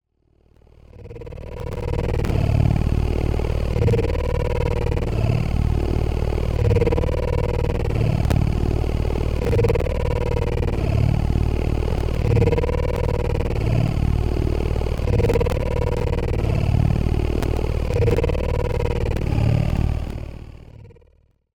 kind of a horrible mechanical cat purr